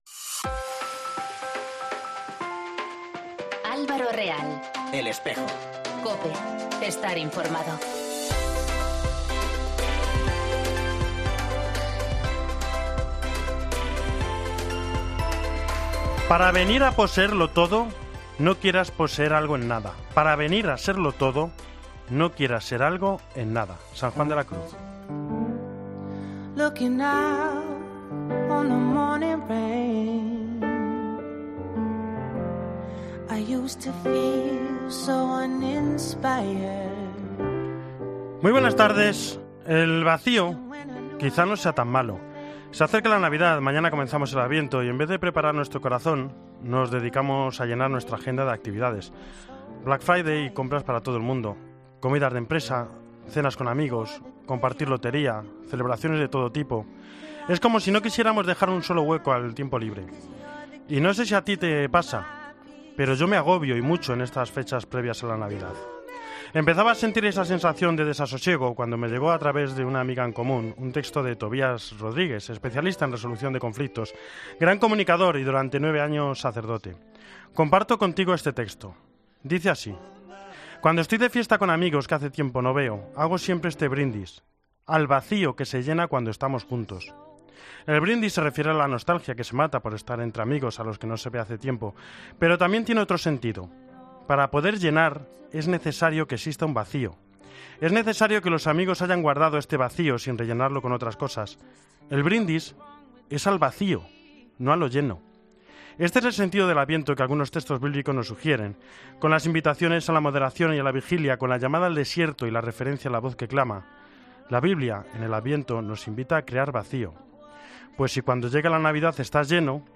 En Espejo 30 noviembre 2019: Monseñor Algora habla d elos 25 años de la Pastoral Obrera en la Iglesia
reportaje